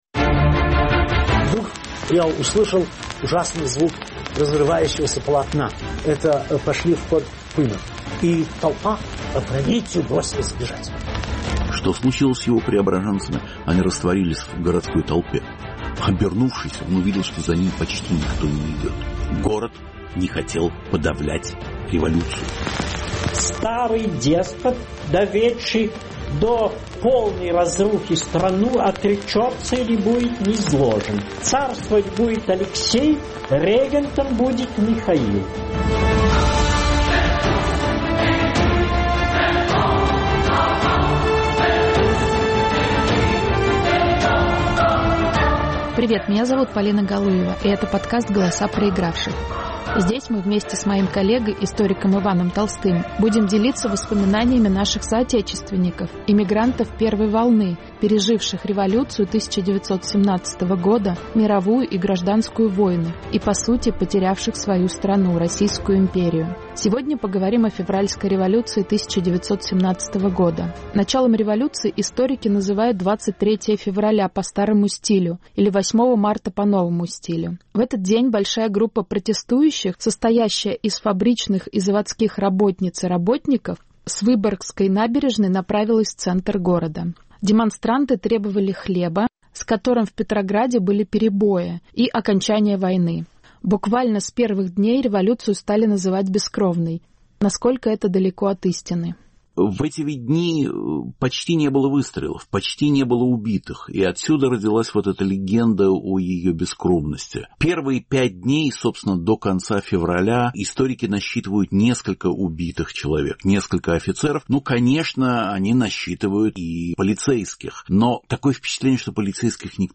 Февральская революция: бескровная? Рассказывают свидетели революционного Петрограда. Повтор эфира от 20 ноября 2022 года.